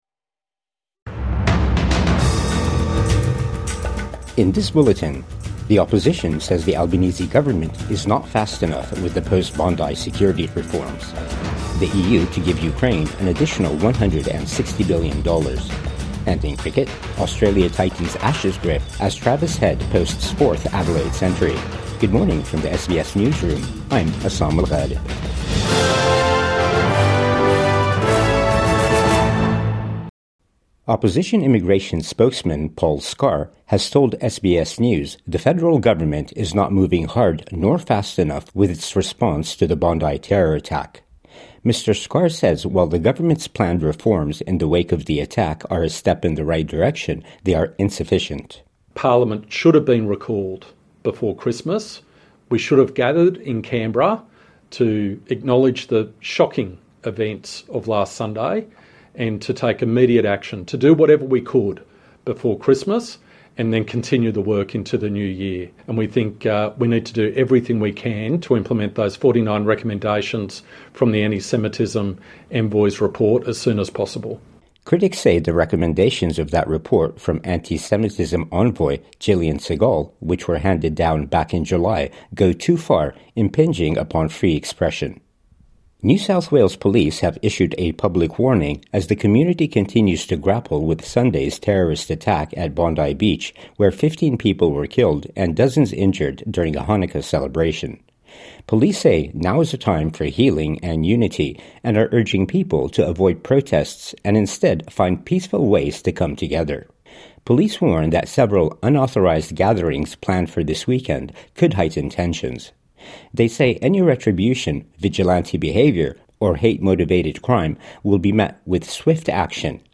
Opposition says Albanese government slow on post-Bondi reforms | Morning News Bulletin 20 December 2025